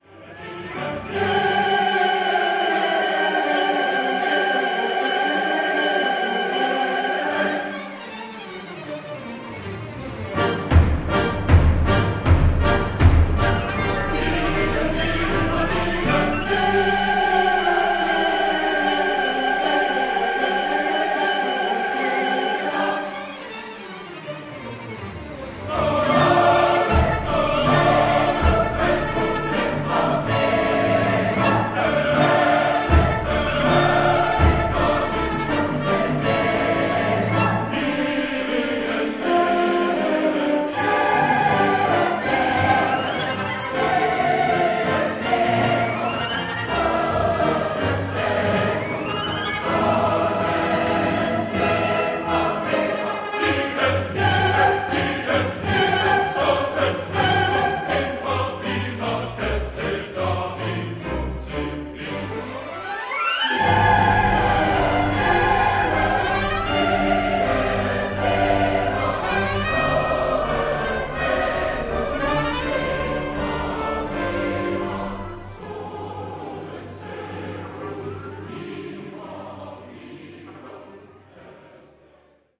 chorus and orchestra
in Sanders Theatre, Harvard University, Cambridge, MA